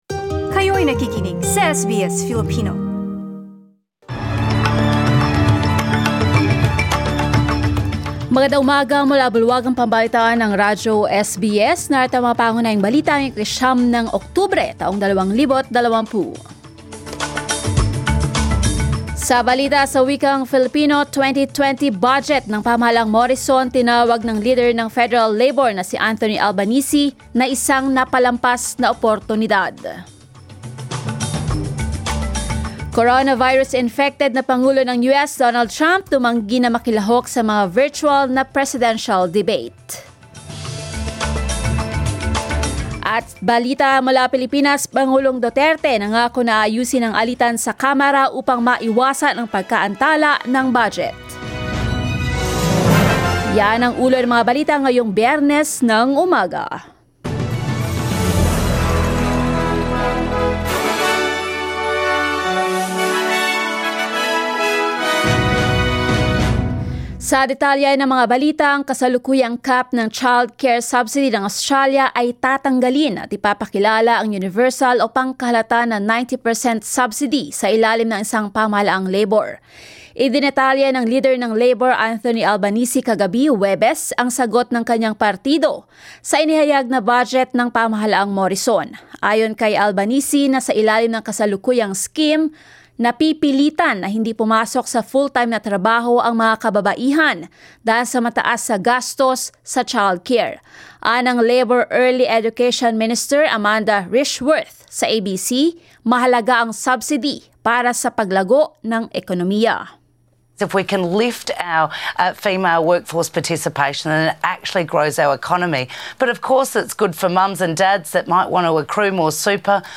SBS News in Filipino, 09 October